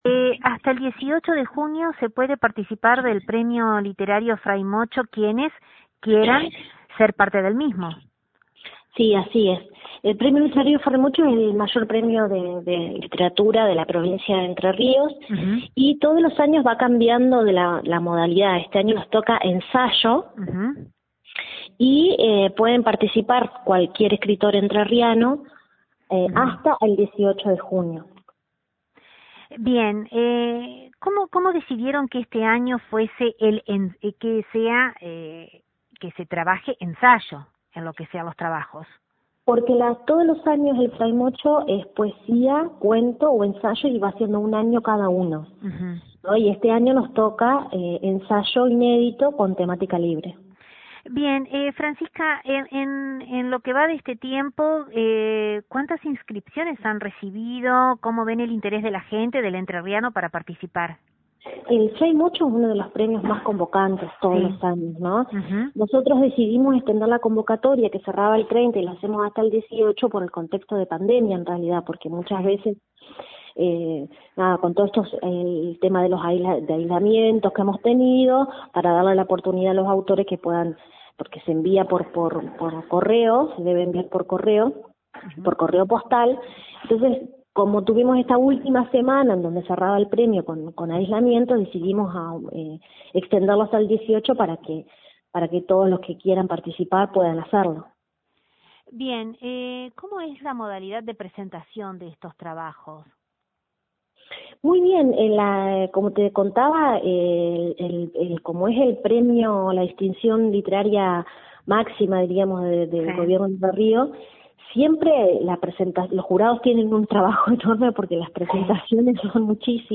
Secretaria de Cultura Entre Ríos, Francisca Dagostino en diálogo con LT39 NOTICIAS